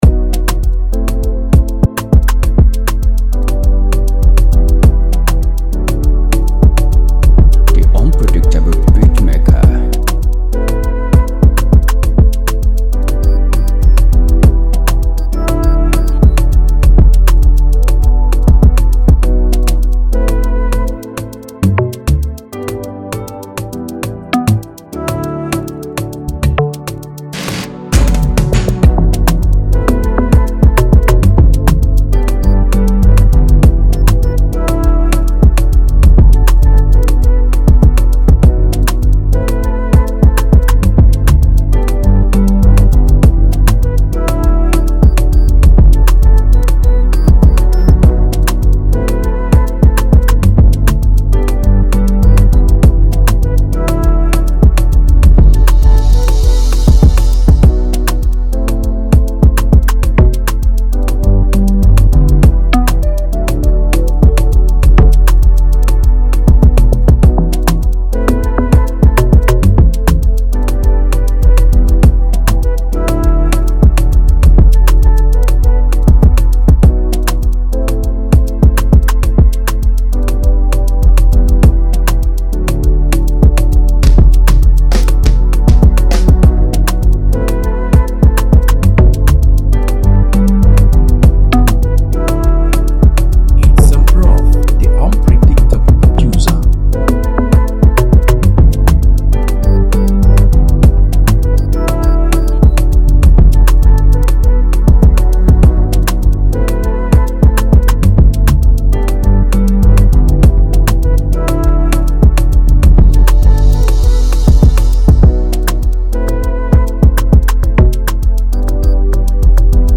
embodies a cool and energetic beat